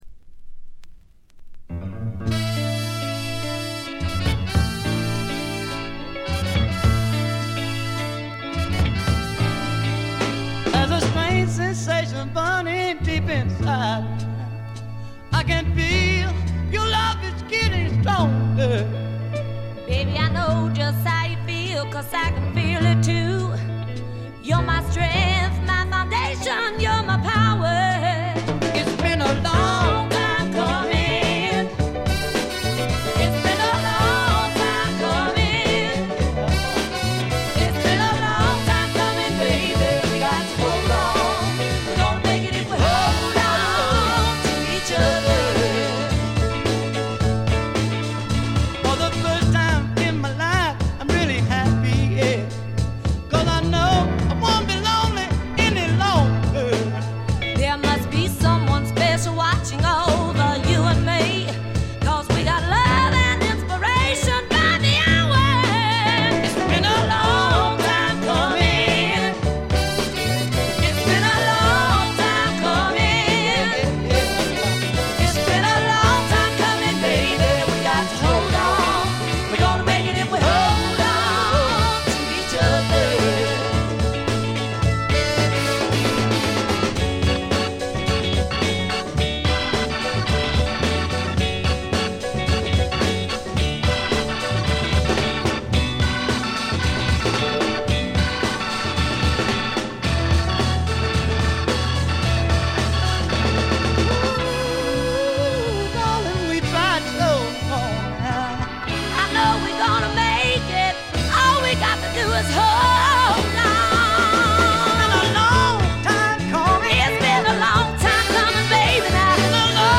ほとんどノイズ感無し。
南部ソウル完璧な一枚。
試聴曲は現品からの取り込み音源です。